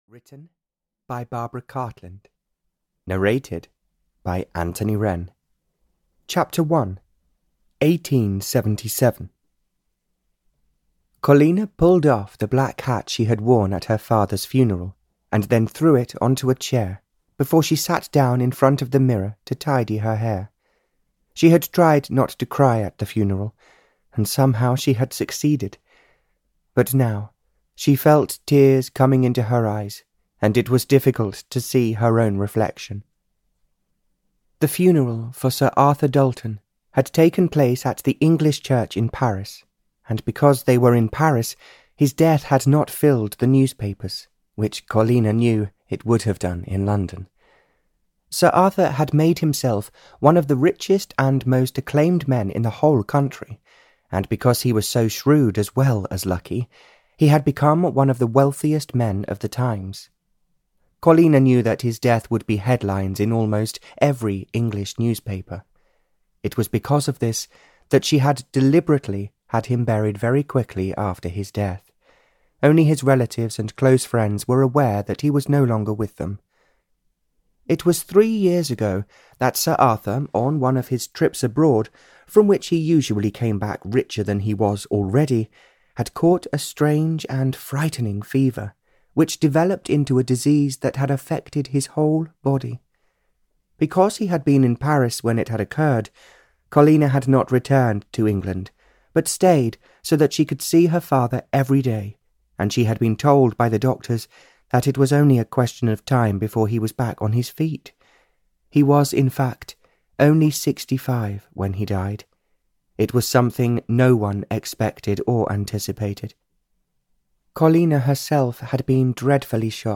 Hiding From the Fortune–Hunters (EN) audiokniha
Ukázka z knihy